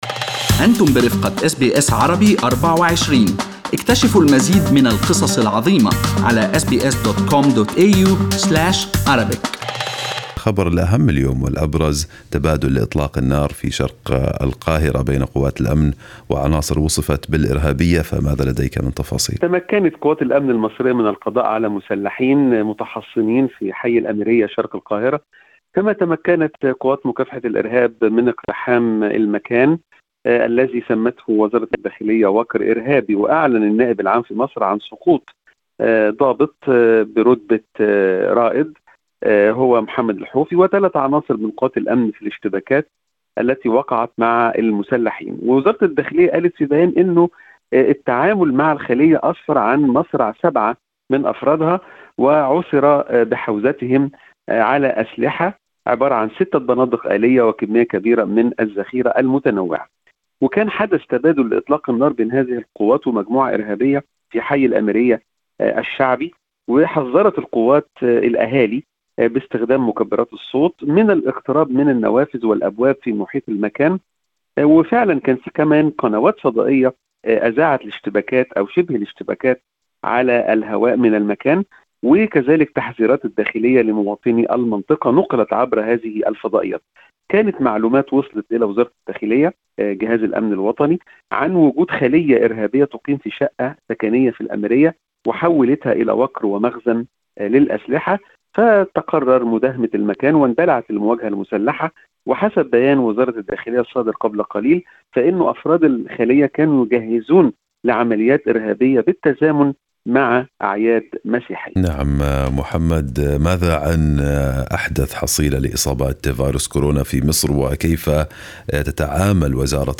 استمعوا إلى التقرير الصوتي أعلى الصفحة.